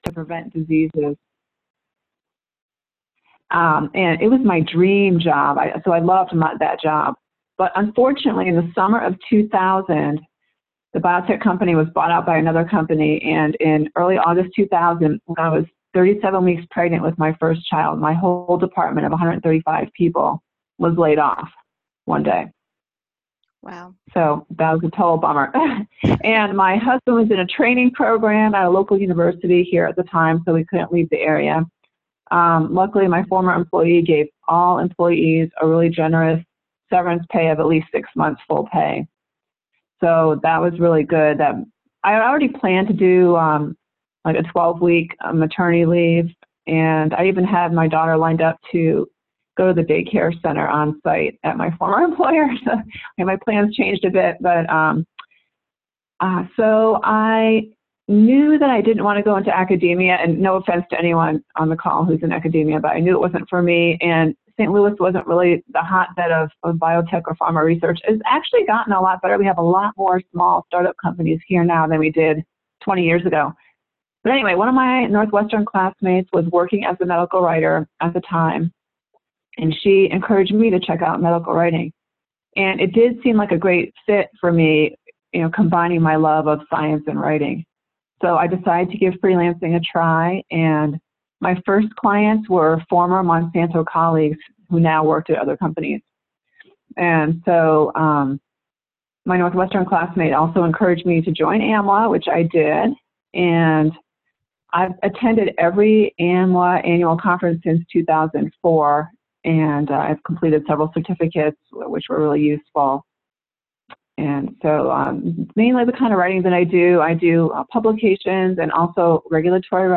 Freelancing Q&A webinar recording.m4a